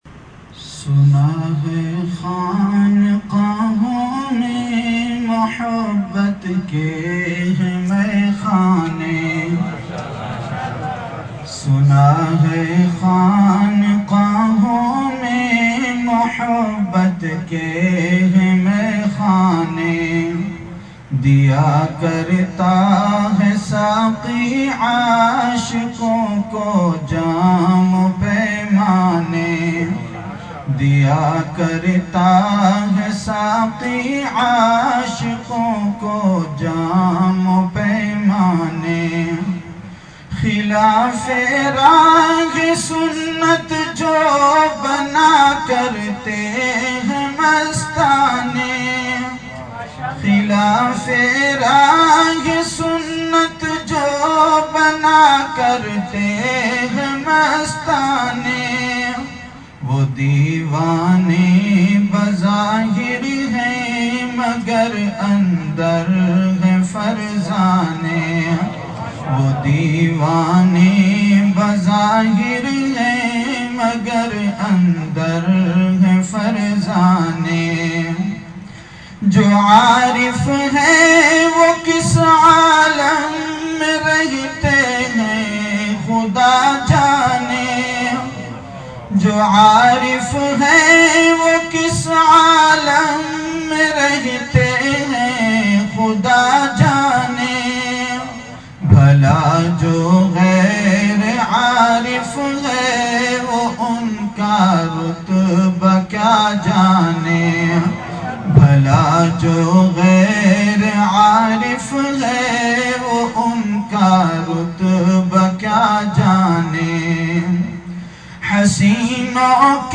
CategoryAshaar
VenueKhanqah Imdadia Ashrafia
Event / TimeAfter Isha Prayer